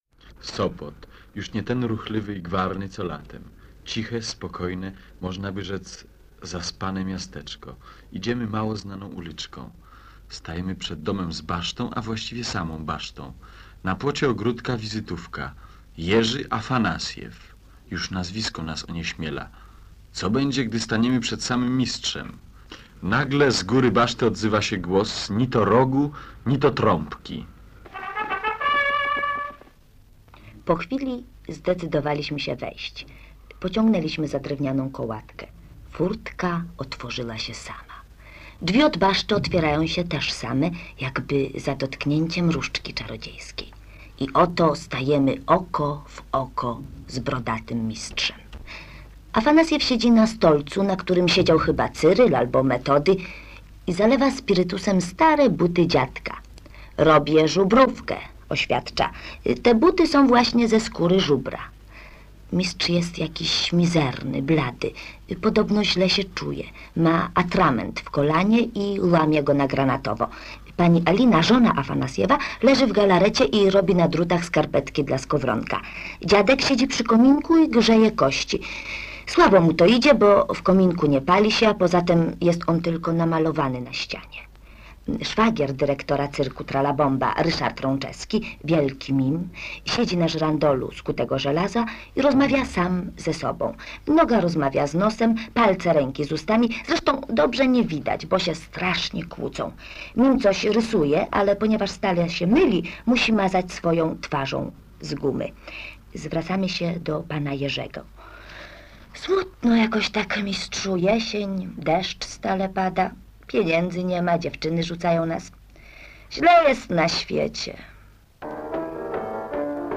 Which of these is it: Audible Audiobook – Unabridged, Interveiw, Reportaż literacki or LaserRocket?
Reportaż literacki